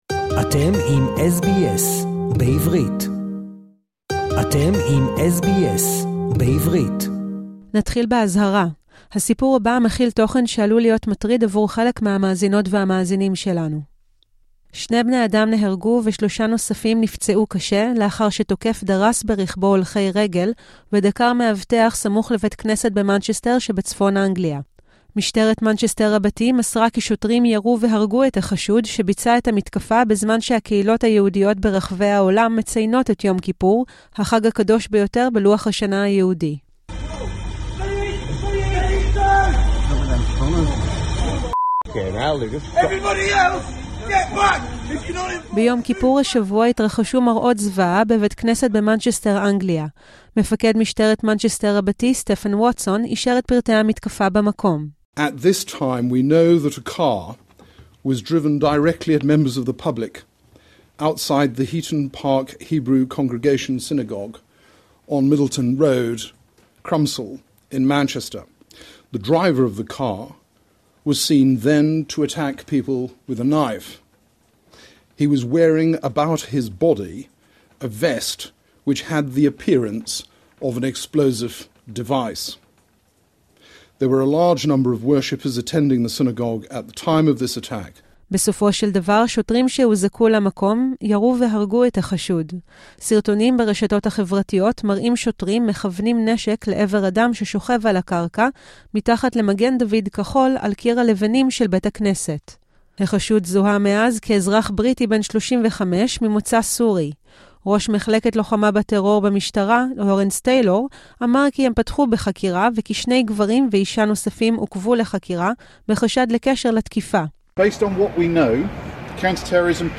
שני בני אדם נהרגו ושלושה נוספים נפצעו קשה לאחר שתוקף דרס ברכבו הולכי רגל ודקר מאבטח סמוך לבית כנסת במנצ'סטר שבצפון אנגליה. משטרת מנצ'סטר רבתי מסרה כי שוטרים ירו והרגו את החשוד, שלטענת החוקרים תקף בדיוק בזמן שהקהילות היהודיות ברחבי העולם מציינות את יום כיפור, החג הקדוש ביותר בלוח השנה היהודי. זהו דיווח מיוחד של SBS בעברית